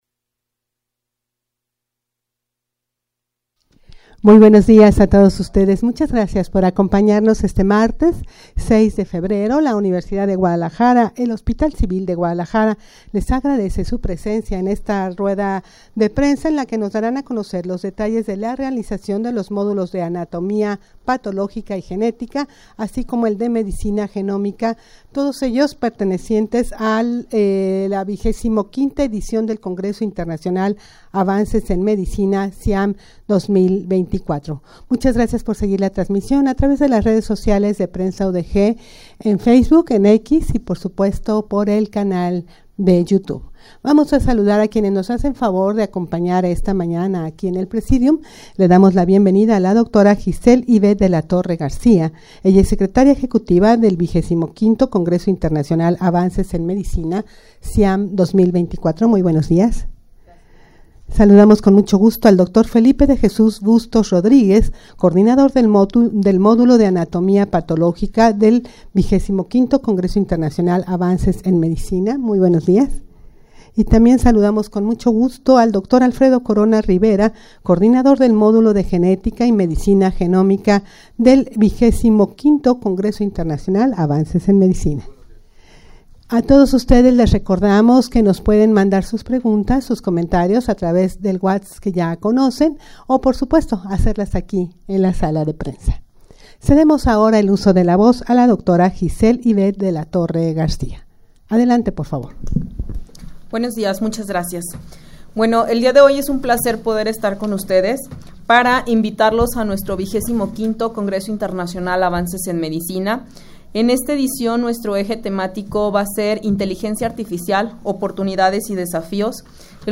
Audio de la Rueda de Prensa
rueda-de-prensa-para-dar-a-conocer-la-realizacion-de-los-modulos-de-anatomia-patologica-y-genetica.mp3